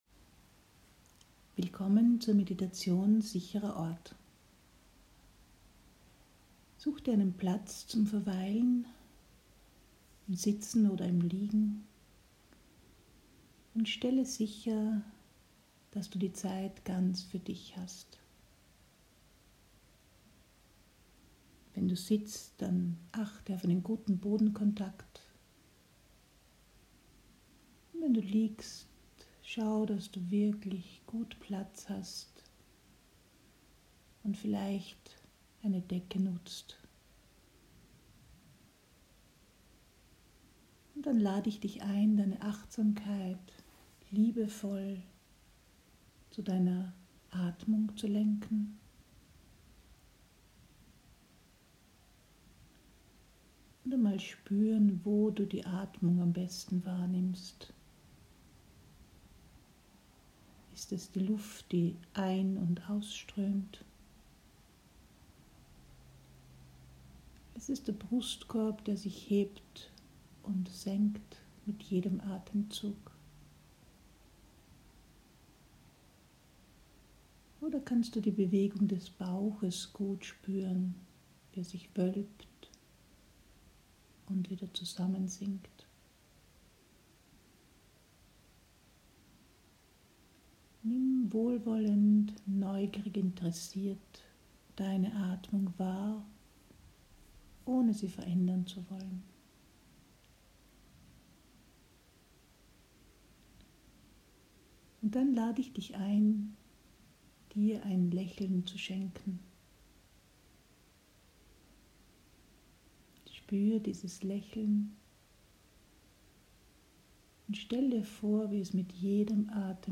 Angeleitete Visualisierung und Medidation